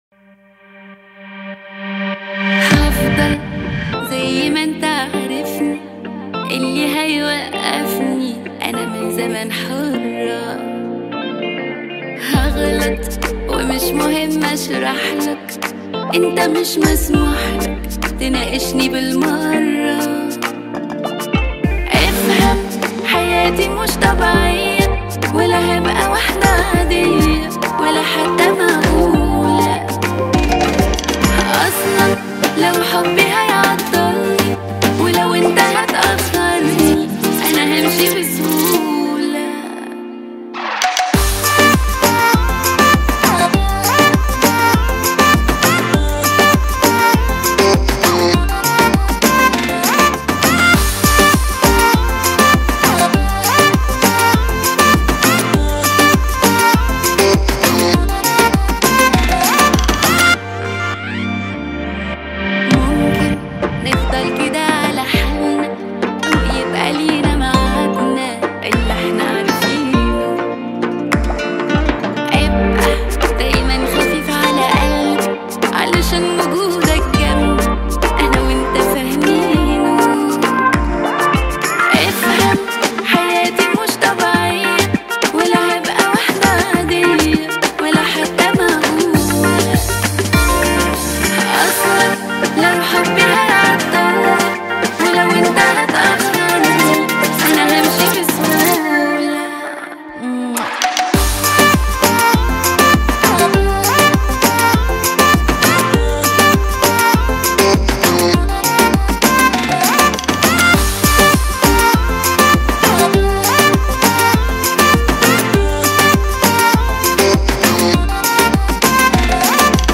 это яркая и запоминающаяся песня в жанре арабской поп-музыки